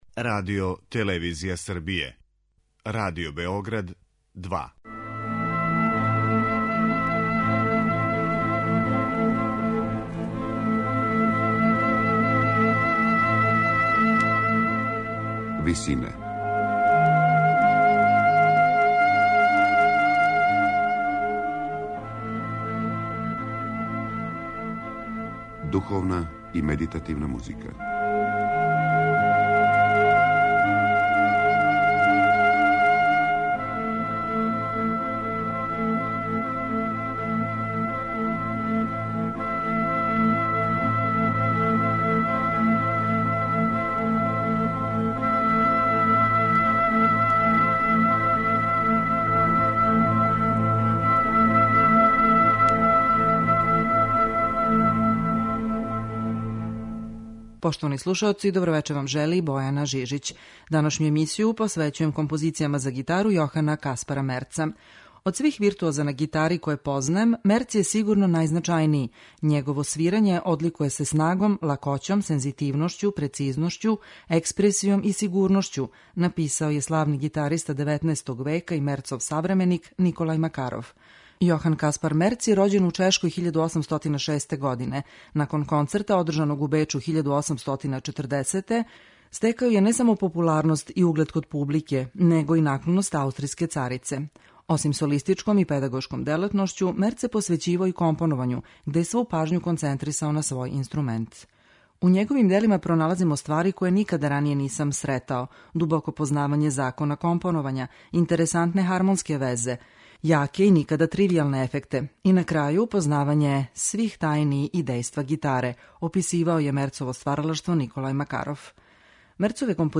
Комади за гитару Јохана Каспара Мерца
У данашњој емисији слушаћете како комаде за гитару Јохана Каспара Мерца изводи Ласло Сендеј Карпер.